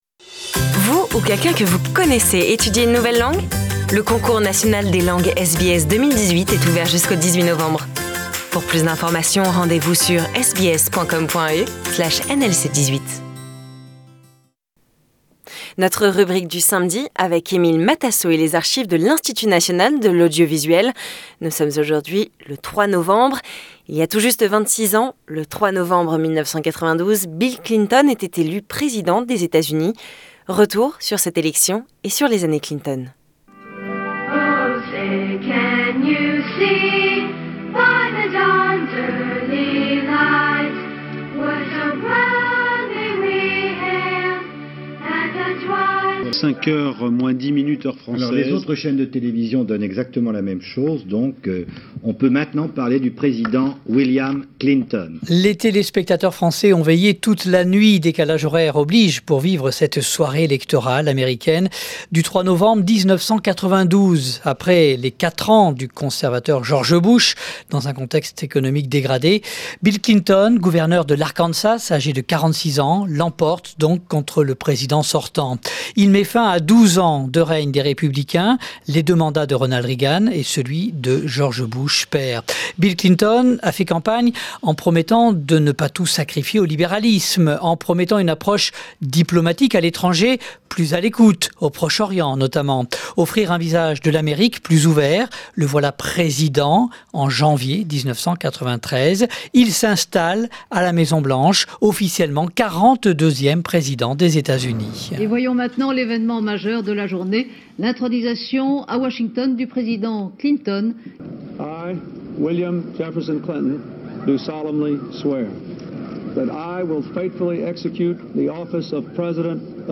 les archives de l'Institut National de l'Audiovisuel. Il y a tout juste 26 ans le 3 novembre 1992, Bill Clinton était élu Président des Etats-Unis. Retour sur cette élection et sur les années Clinton.